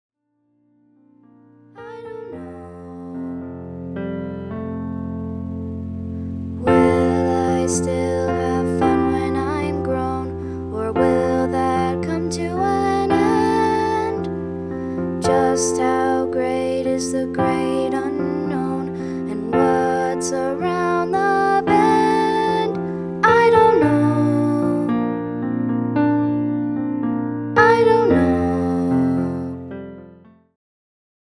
designed for young voices